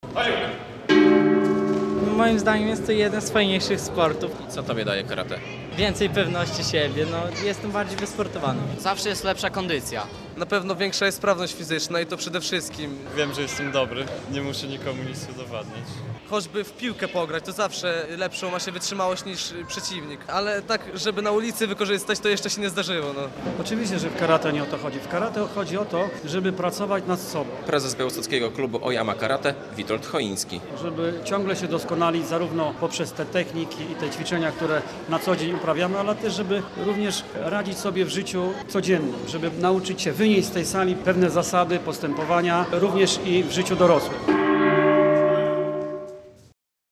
20-lecie Oyama Karate - relacja